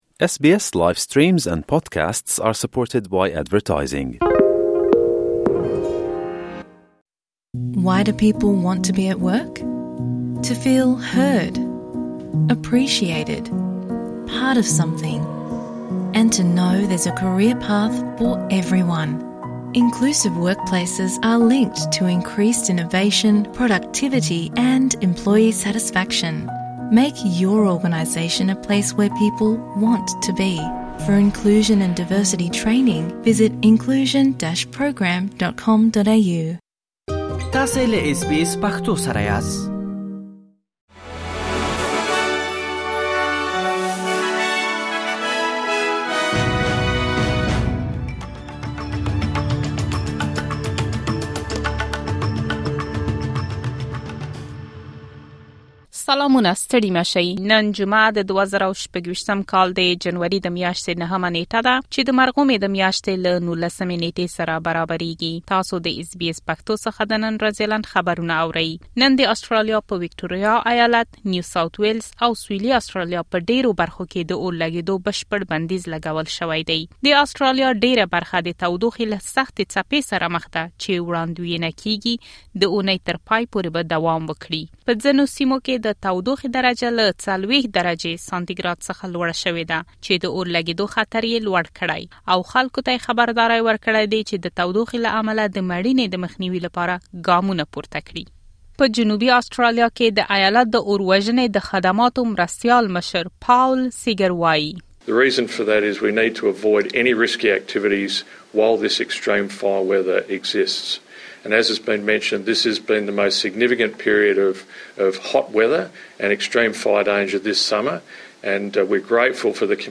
د اس بي اس پښتو د نن ورځې لنډ خبرونه |۹ جنوري ۲۰۲۶
د اس بي اس پښتو د نن ورځې لنډ خبرونه دلته واورئ.